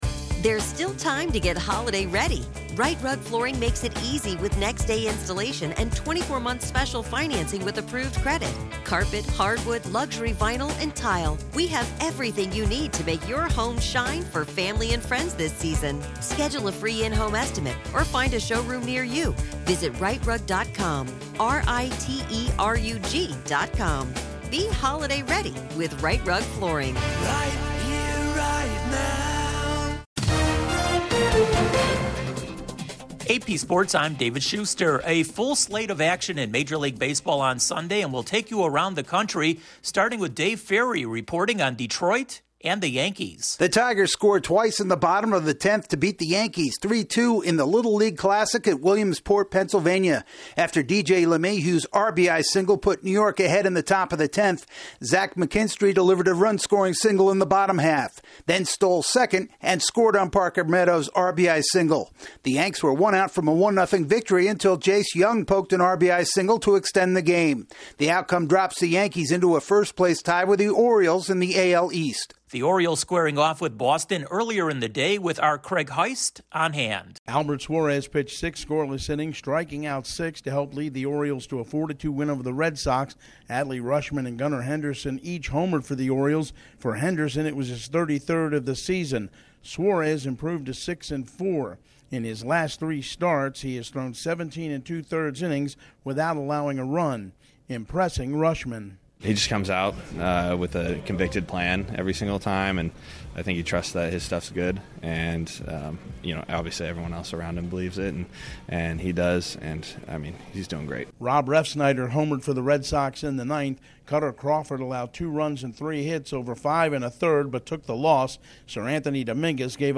The Yankees and Orioles are back in a first-place tie in the A.L. East, Hideki Matsuyama wins the first leg of the FedEx Cup Championship and the Raiders decide on their season-opening quarterback. Correspondent